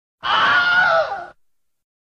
wilhelmscream.ogg